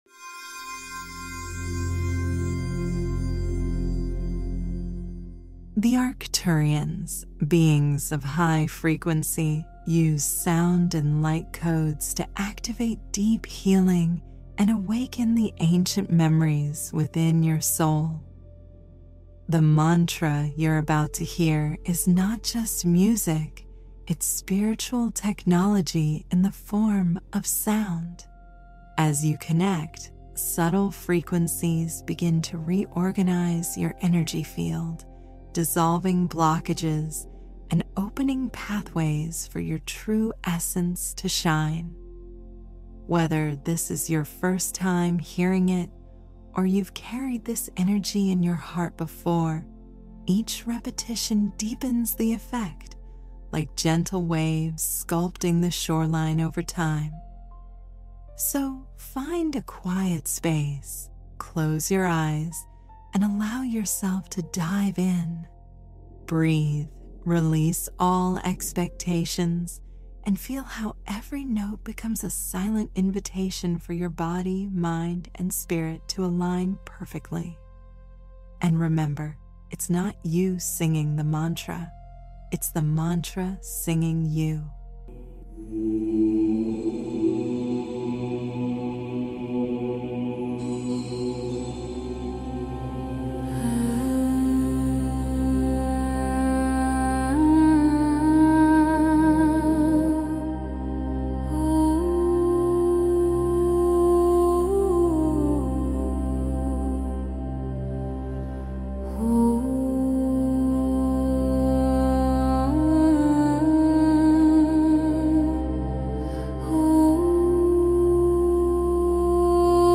¡La sanación del futuro ha llegado hoy! 🌌 Sumérgete en una poderosa experiencia de sanación con este mantra Arcturiano, una tecnología espiritual diseñada para sanar y elevar tu vibración.